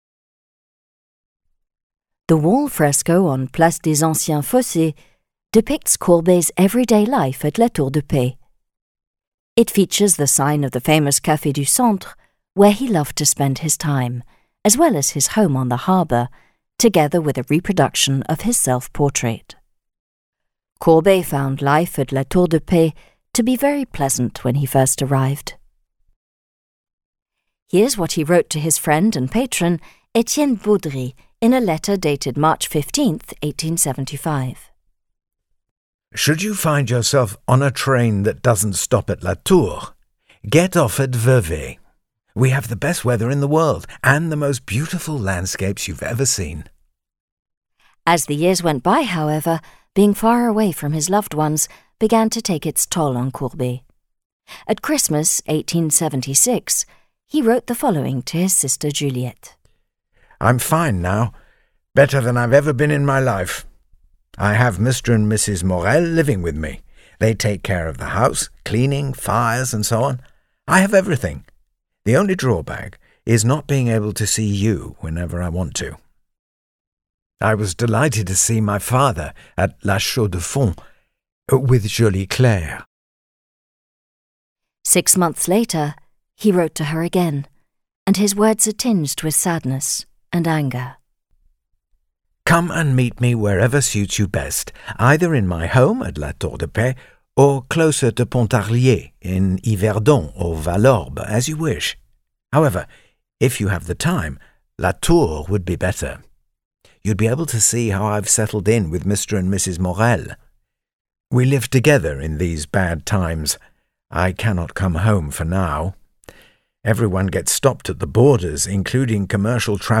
Extract of the France 3 TV documentary: Bourgogne Franche-Comté